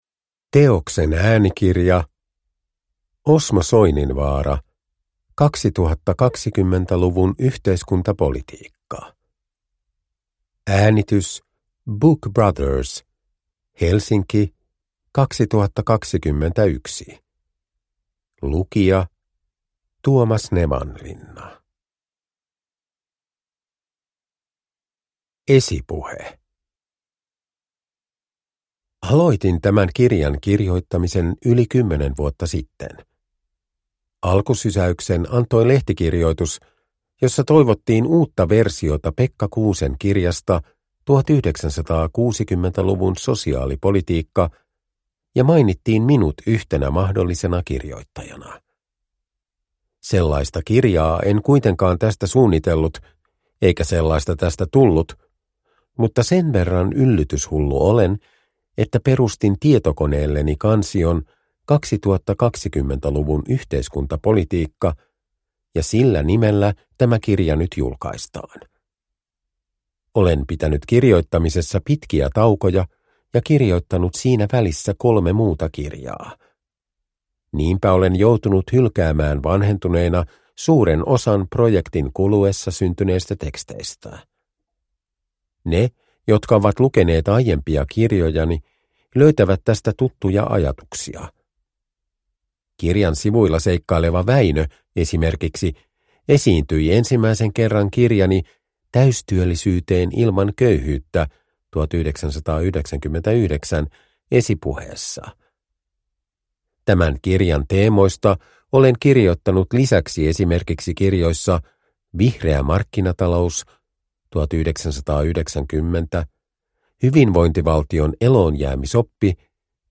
2020-luvun yhteiskuntapolitiikka – Ljudbok – Laddas ner
Uppläsare: Tuomas Nevanlinna